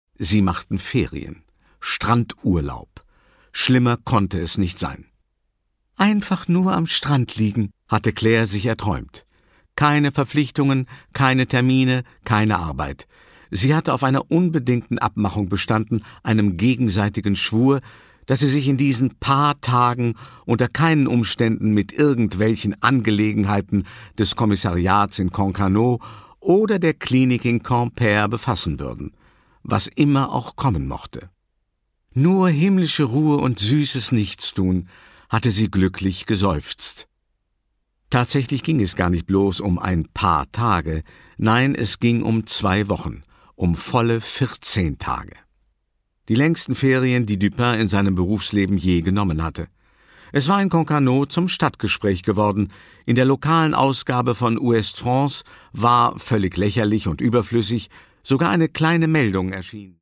Produkttyp: Hörbuch-Download
Gelesen von: Gerd Wameling